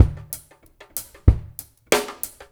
ABO DRUMS1-R.wav